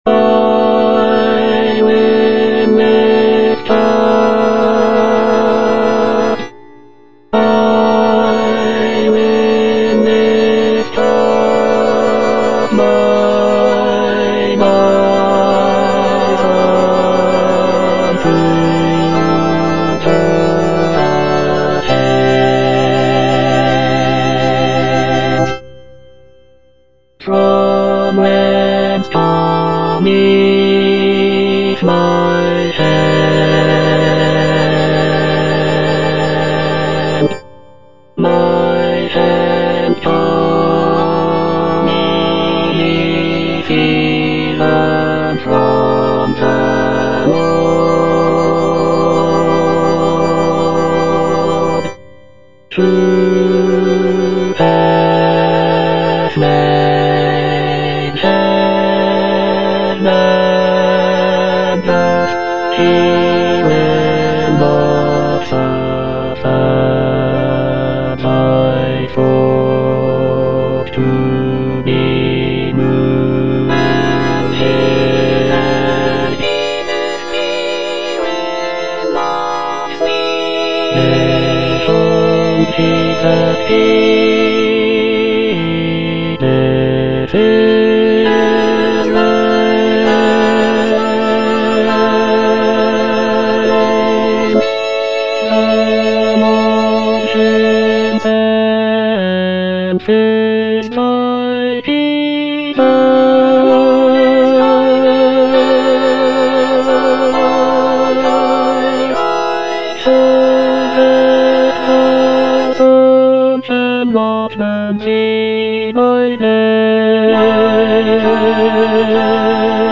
Bass II (Emphasised voice and other voices)
is a choral work